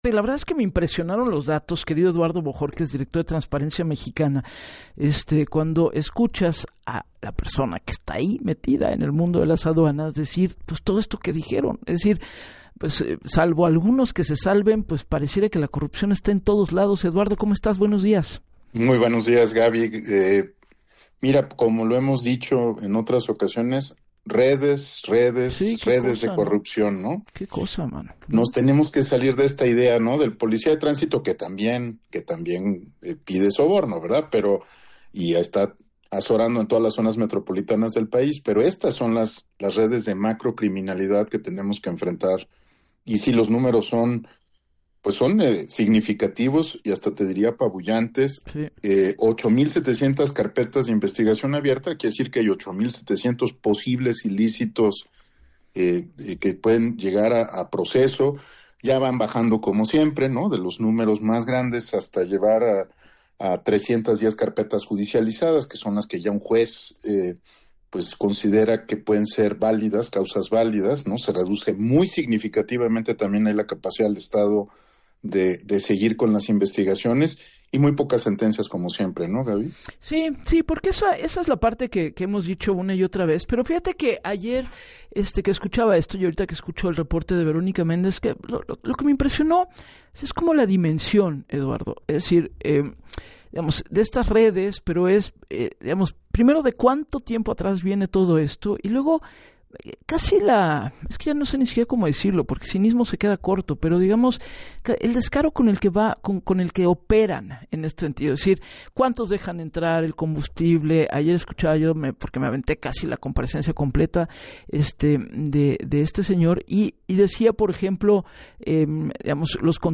En entrevista con Gabriela Warkentin para el programa Así las Cosas